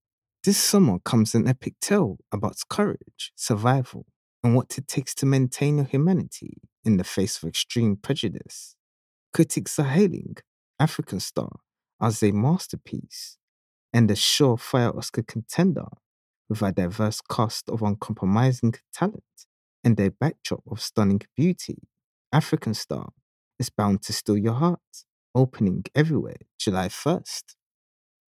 I have a smooth, trustworthy voice, with a natural london-tinged RP accent, which I can make straig...